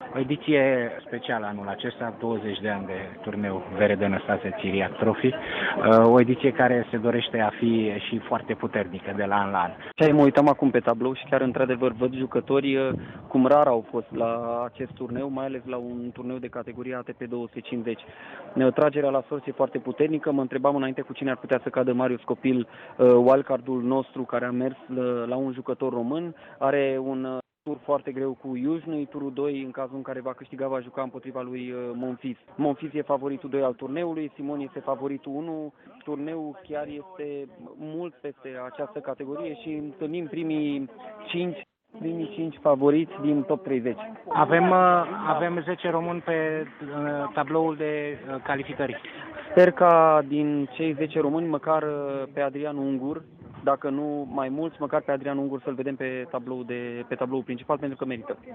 interviu-teni.mp3